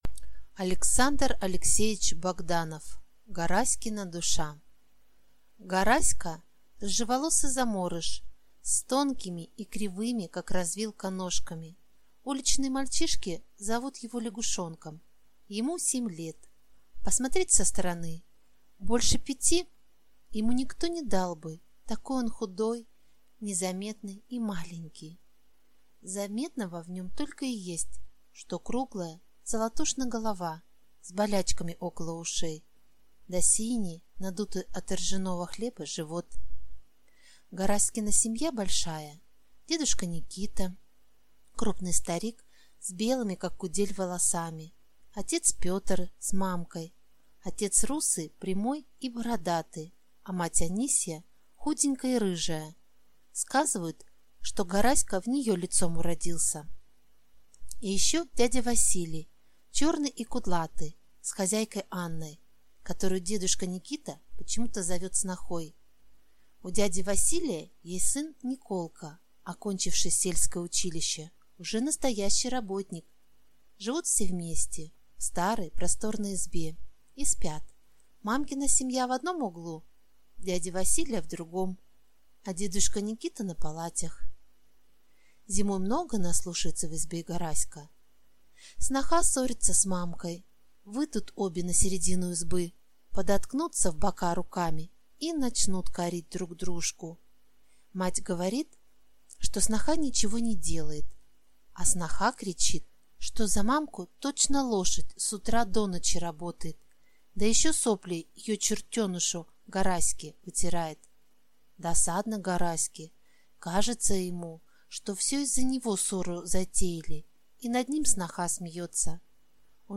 Аудиокнига Гараськина душа | Библиотека аудиокниг